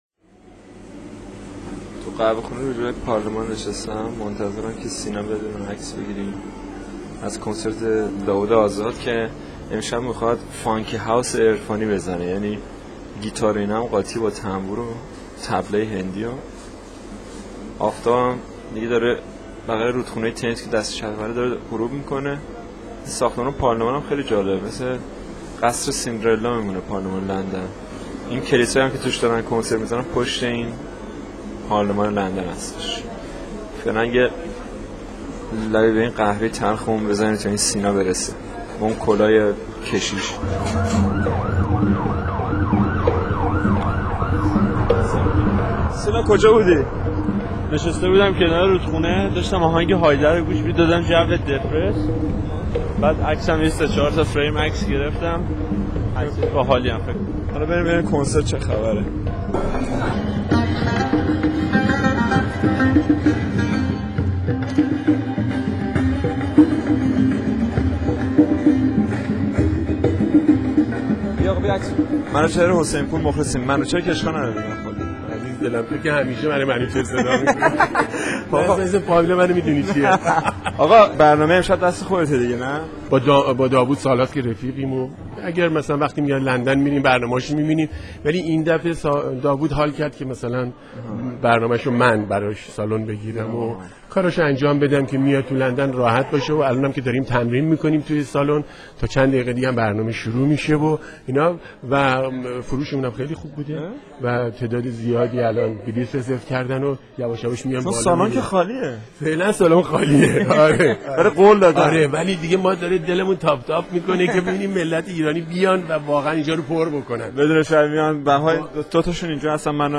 صدای گزارش از شب کنسرت - قسمت اول